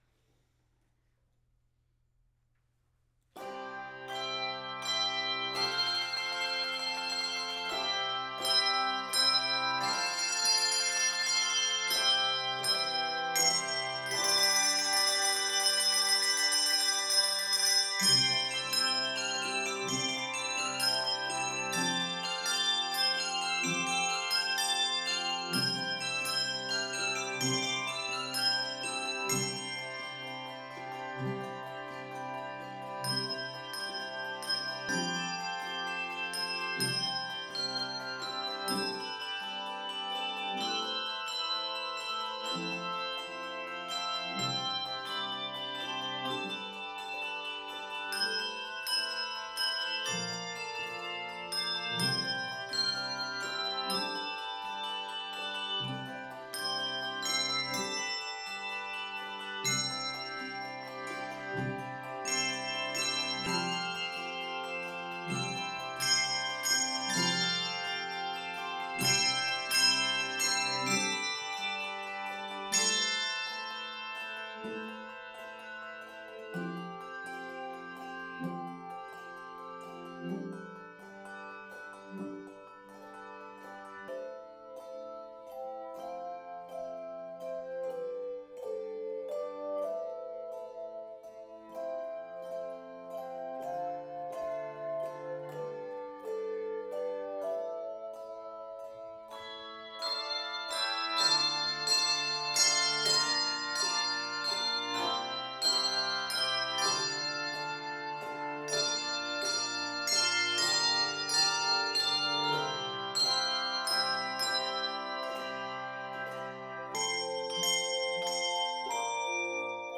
Voicing: Handbells 3-6 Octave